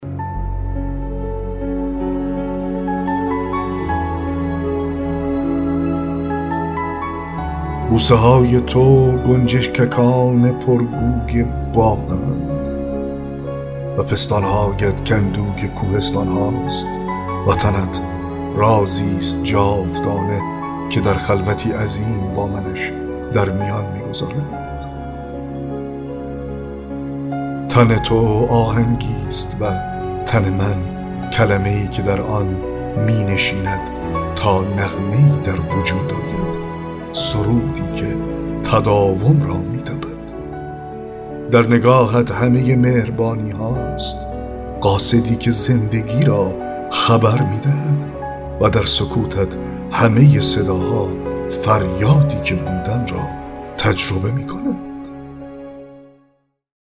7- دکلمه شعر سرود برای سپاس و پرستش (بوسه های تو گنجشککان پرگوی باغند…)